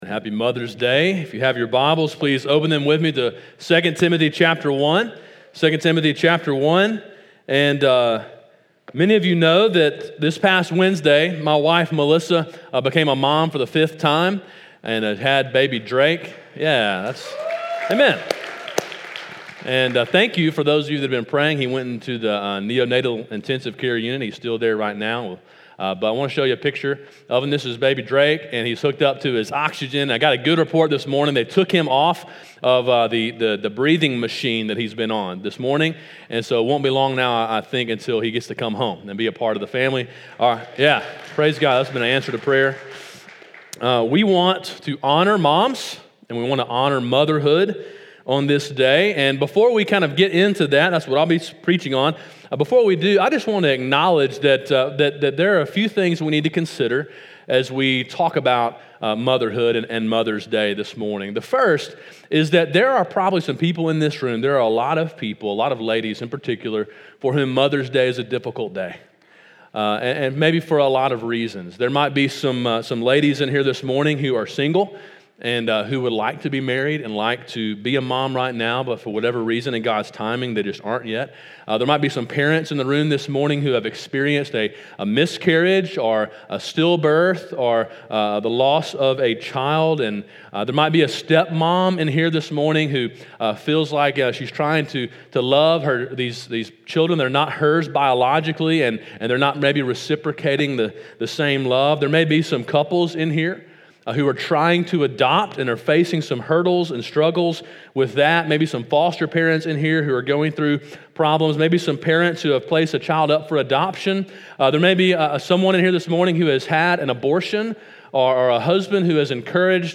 Sermon: “World’s Best Mom” (2 Timothy 1:5)